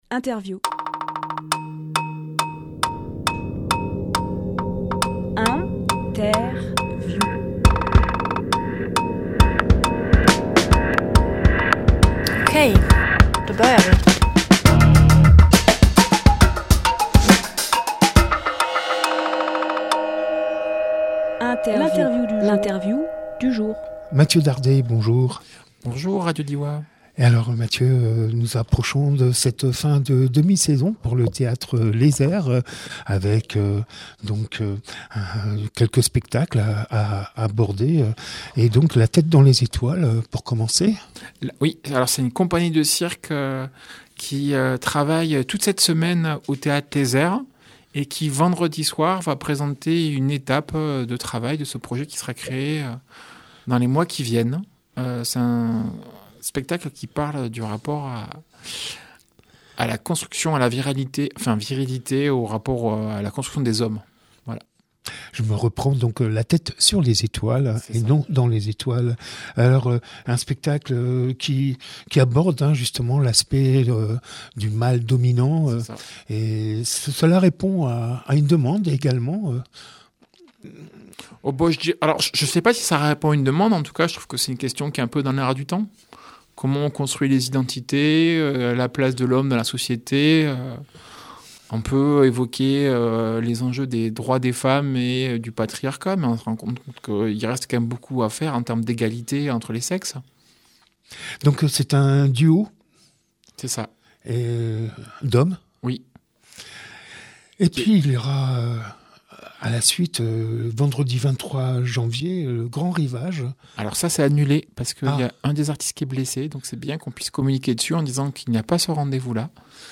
Emission - Interview Trottola présente Strano Publié le 12 janvier 2026 Partager sur…
lieu : Studio RDWA